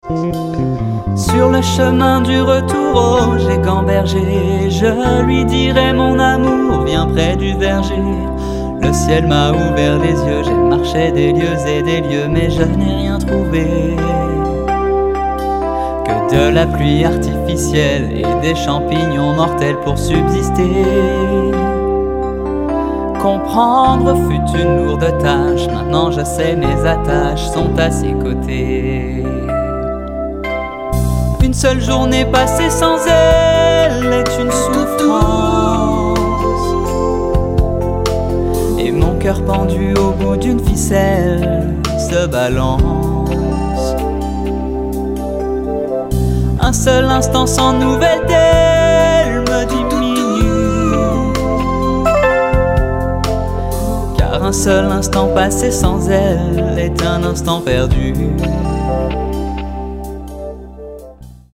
Tessiture : ténor